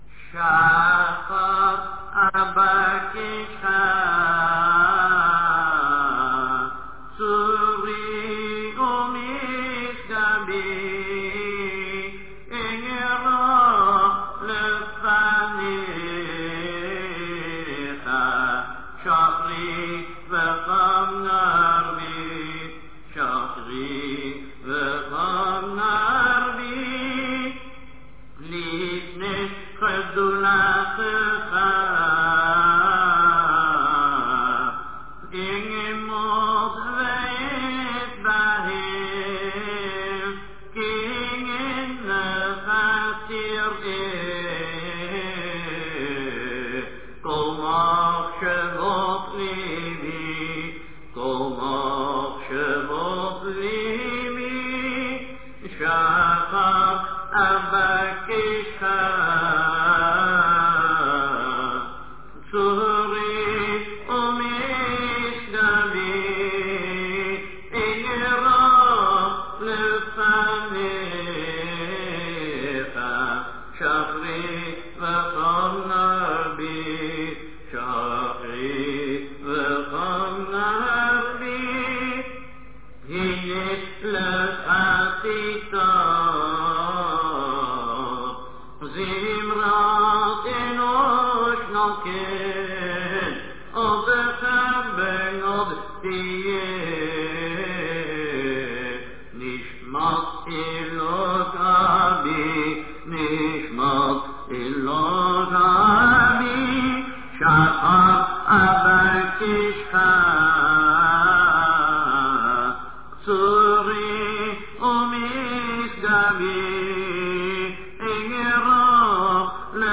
Various chazzanut clips